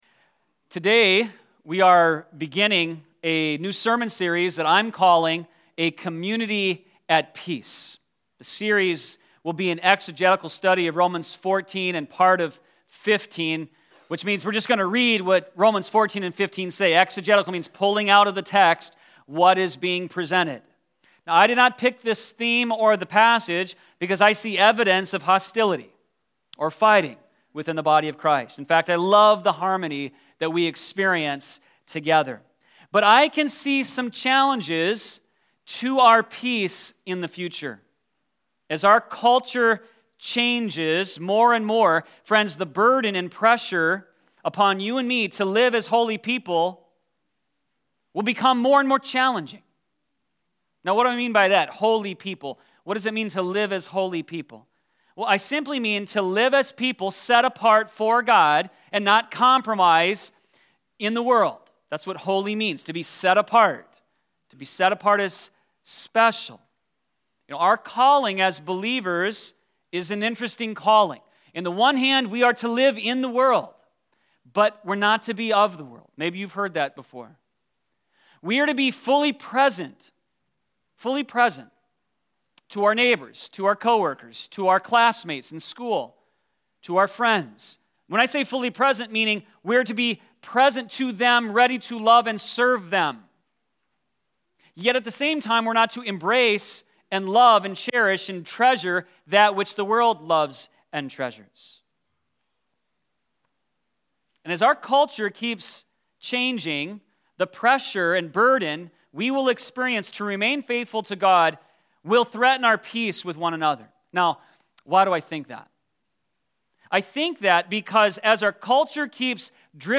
Sermon012217_2.mp3